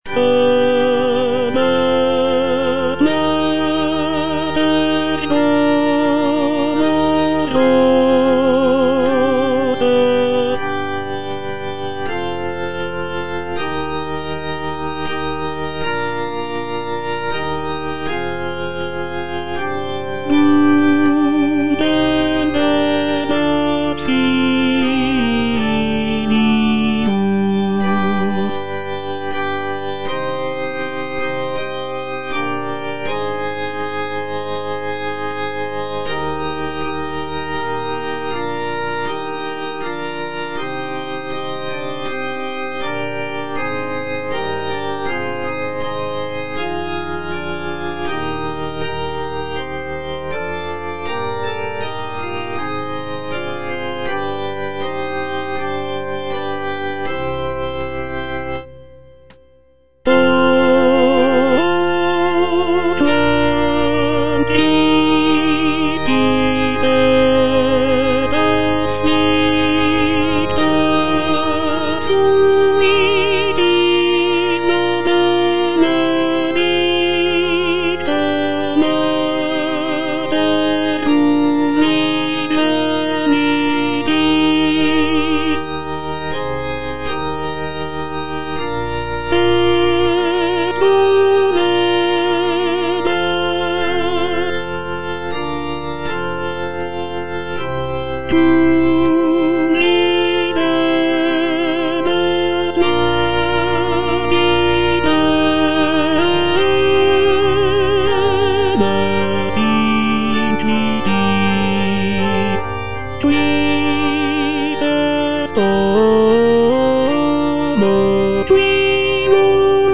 G.P. DA PALESTRINA - STABAT MATER Stabat Mater dolorosa (tenor I) (Voice with metronome) Ads stop: auto-stop
sacred choral work